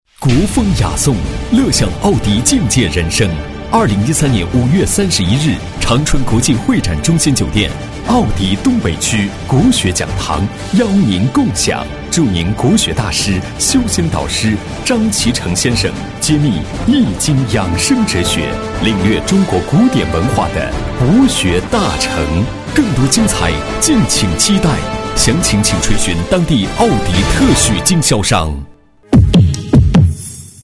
男国167厚重配音-新声库配音网
8 男国167_广告_汽车_奥迪活动广告宣传 男国167
男国167_广告_汽车_奥迪活动广告宣传.mp3